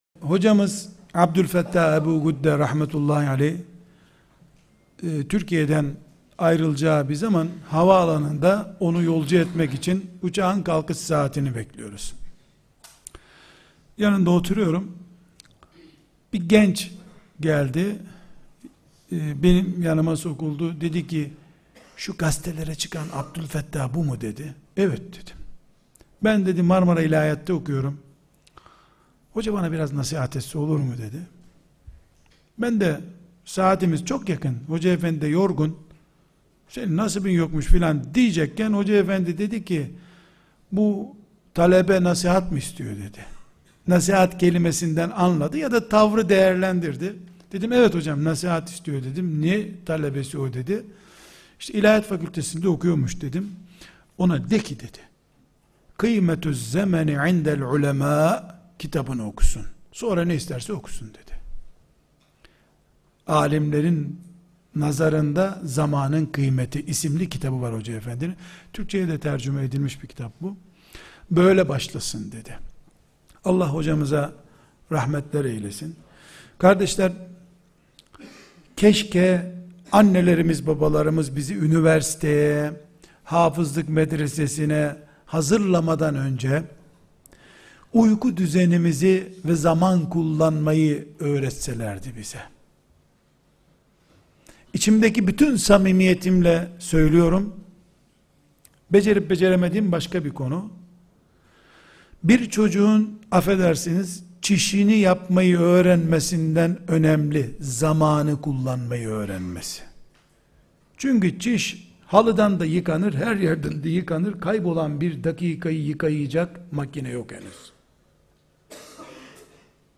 1. Sohbet Arşivi
2. Soru & Cevap